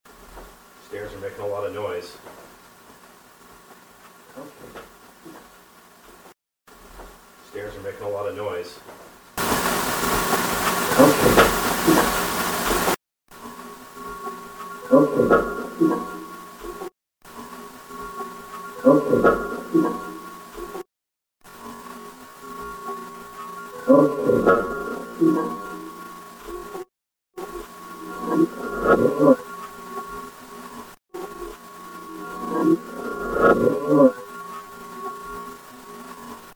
Knowing this house was inhabited by at least one spirit, we brought along a digital camera and the Sony audio recorder.
This one is fairly clear, maybe one of the best EVP's we've ever recorded. Again you hear the original, then the amplified version. After that you hear noise reduction twice, slowed down, and reversed. There are actually two voices in this clip, one at the very end that overlaps the voice saying "comfortable".